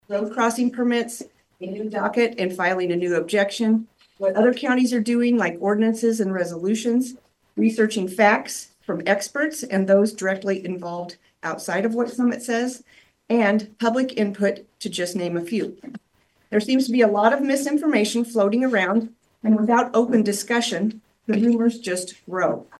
During the public comment period, the speakers consisted of most property owners affected by the pipeline’s proposed route. The speakers discussed the need for the supervisors to have an open discussion on the pipeline on next week’s agenda.